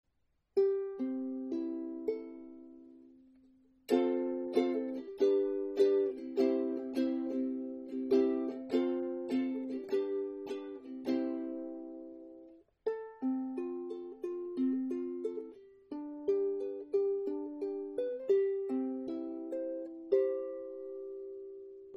Meine Soundbeispiele sind alle am gleichen Tag mit einem Zoom H2 aufgenommen.
Gespielt habe ich nur ein paar Akkorde.
Korpus Sperrholz, rot lackiert, rote Kala Saiten
Sie ist bundrein, klingt (auch mit den nicht so tollen Kala Saiten) erstaunlich gut.